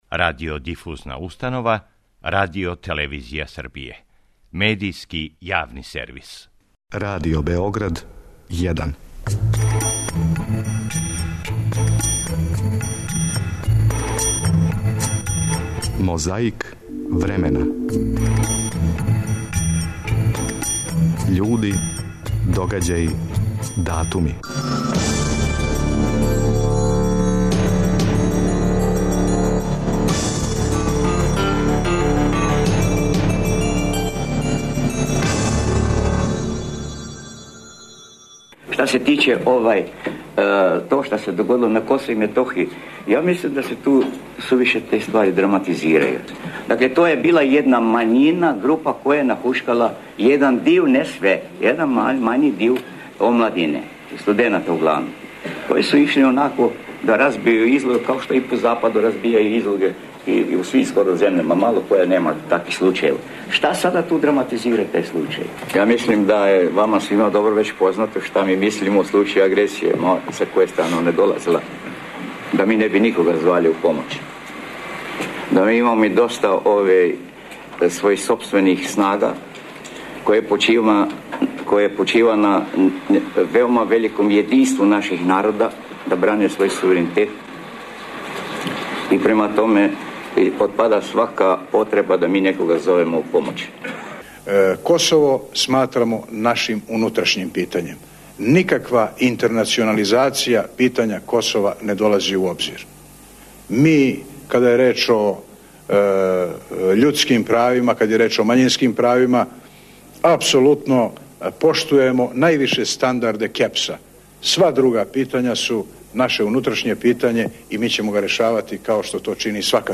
Иста тема, различити говорници.